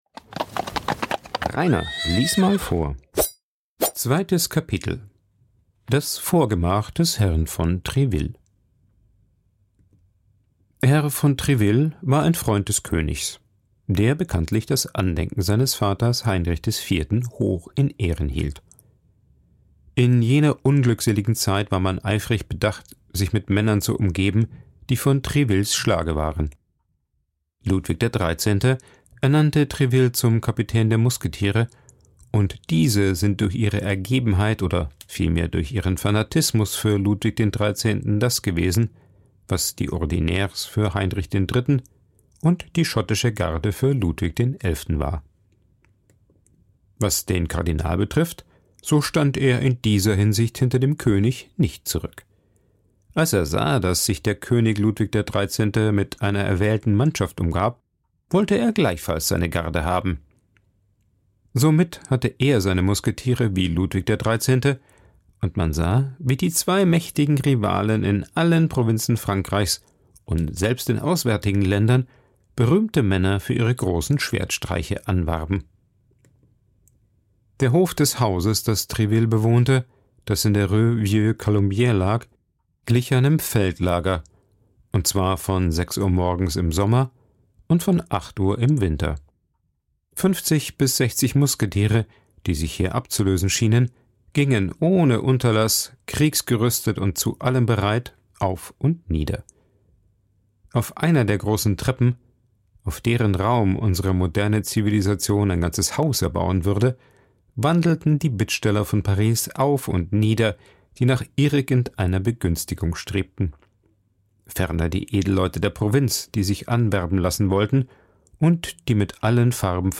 Beschreibung vor 2 Jahren 2. Das Vorgemach des Herrn von Tréville Wir begleiten D'Artagnan auf dem Weg zu Herrn von Tréville und treffen bei dieser Gelegenheit die Musketiere Porthos und Aramis auf den Treppen zum Vorgemach. Porthos gibt sich als Kavalier mit leichtem Hang zum Blenden, während bei Aramis noch nicht geklärt ist, ob er Musketier bleibt oder sich doch der Kirche zuwendet. Vorgelesen
aufgenommen und bearbeitet im Coworking Space Rayaworx, Santanyí, Mallorca.